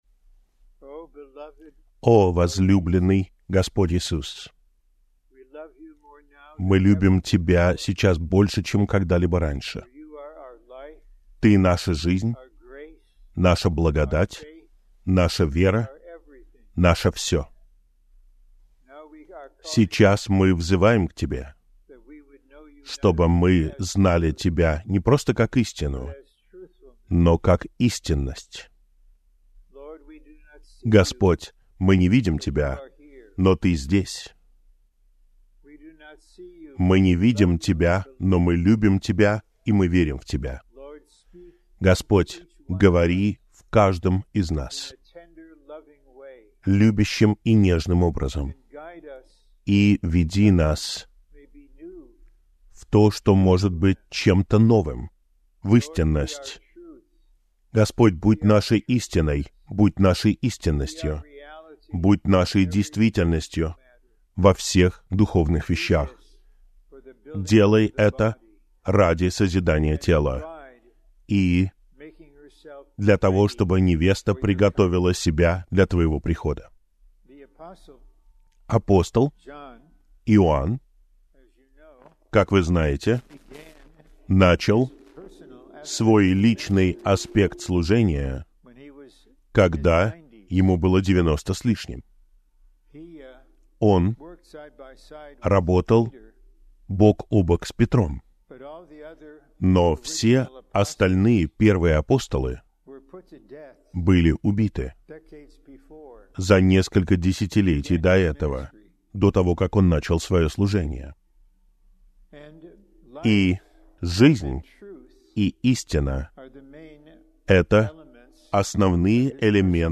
Юго-Восточная конференция смешивания в Джексонвилле, Флорида, США, сентябрь 2024 года